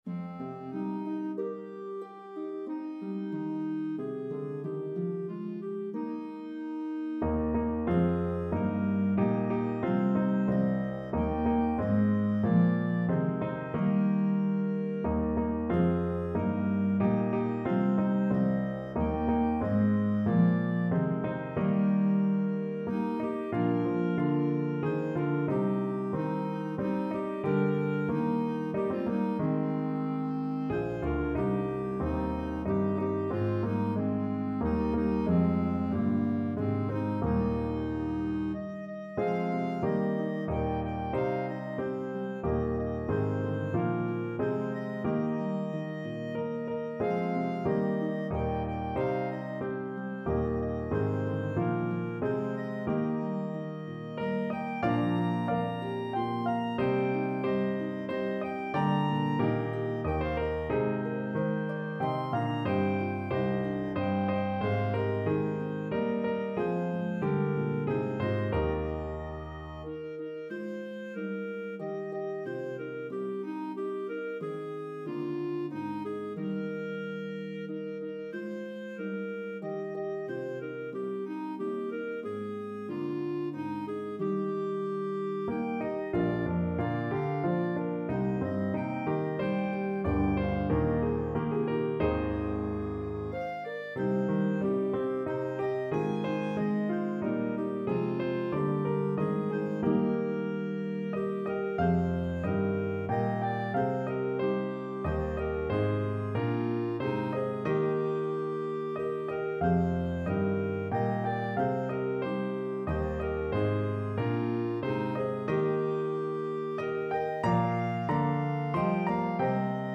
A meditative trio arrangement
pentatonic hymn tune